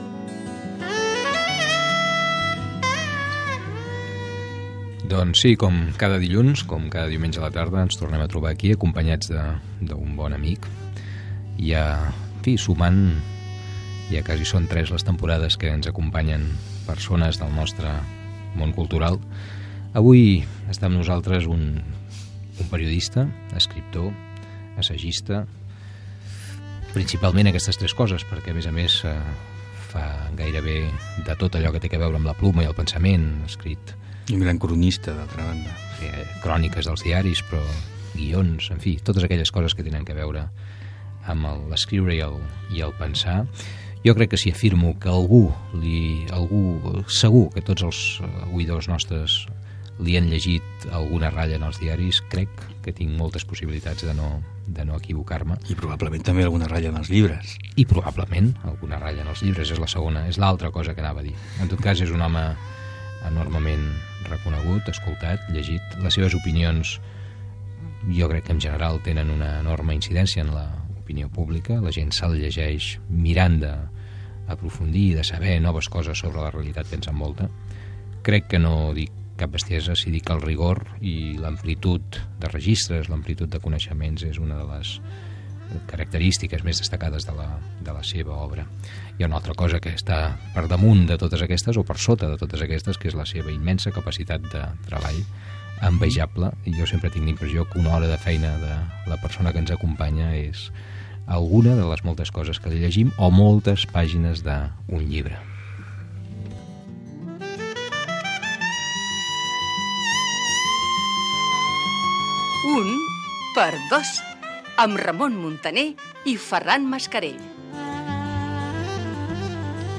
Presentació, indicatiu del programa, entrevista a l'escriptor Manuel Vázques Montalbán